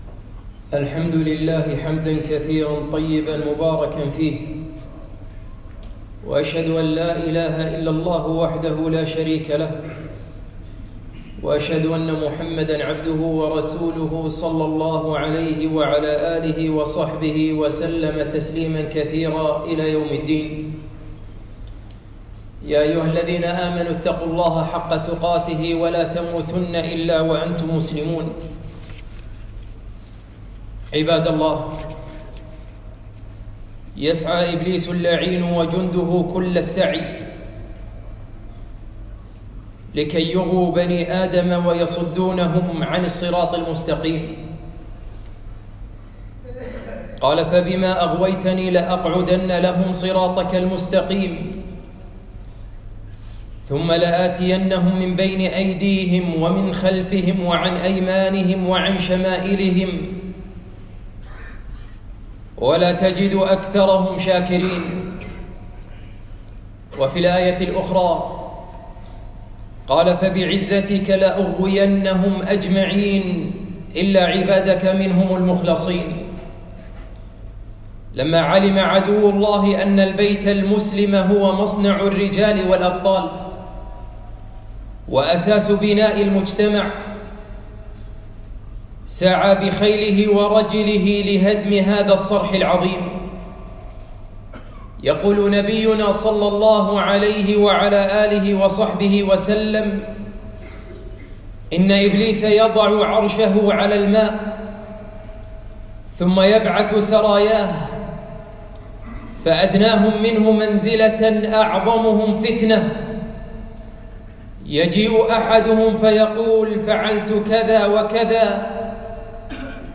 خطبة - تحصين البيوت